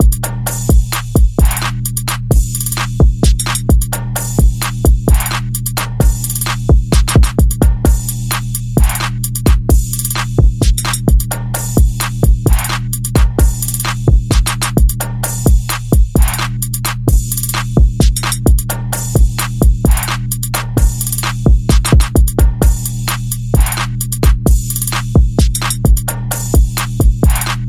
XM2型鼓循环
描述：具体的调子是B小调。 包括808，Kick，小鼓，Hi Hats和打击乐器。
Tag: 130 bpm Trap Loops Drum Loops 4.66 MB wav Key : B